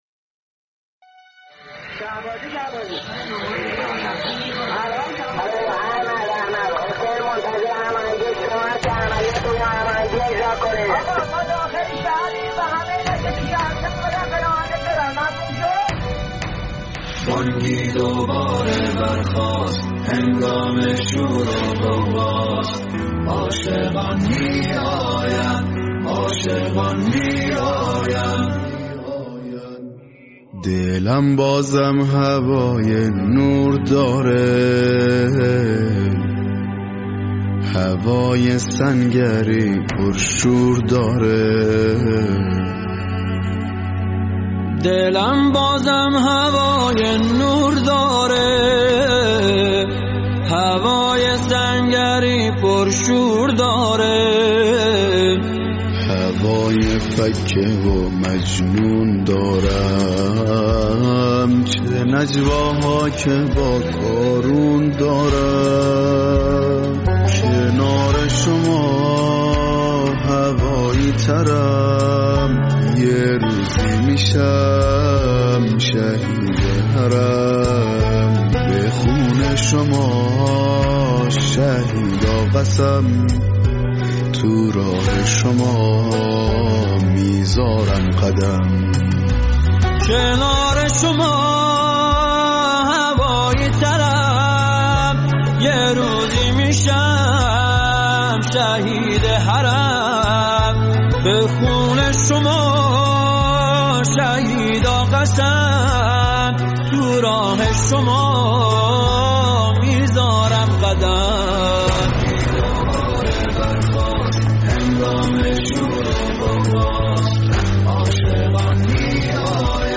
سرود ارکسترال با مضمون کاروان های راهیان نور بانگی دوباره برخاست هنگام شور و غوغاست عاشقان می آیند عاشقان می آیند.